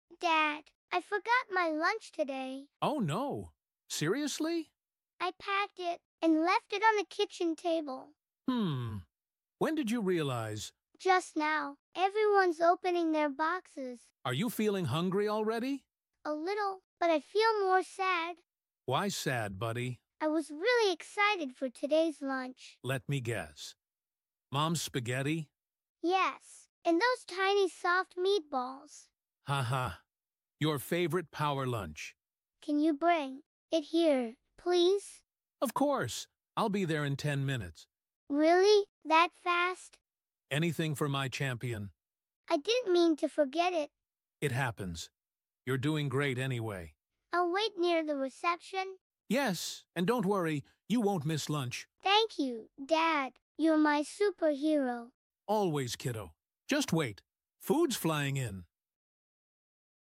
speaking practice through dialogue.